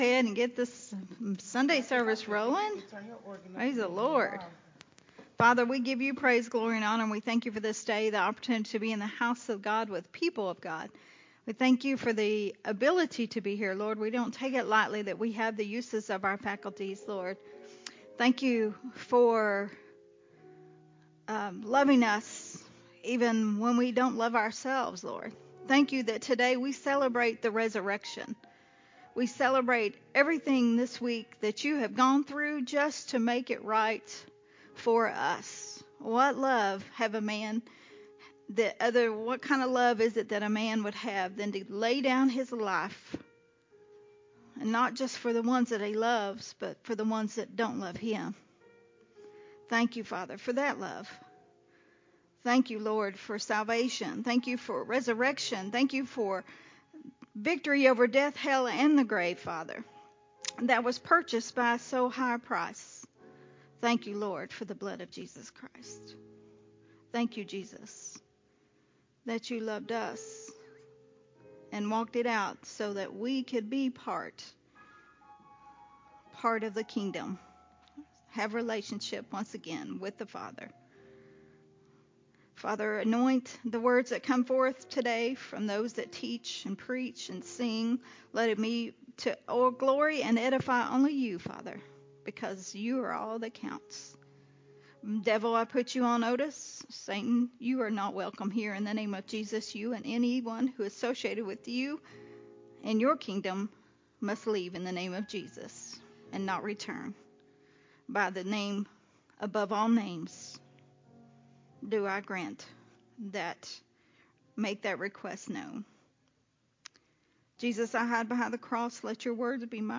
recorded at Unity Worship Center on April 9th, 2023.